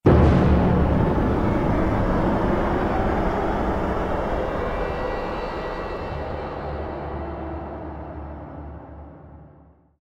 attack.ogg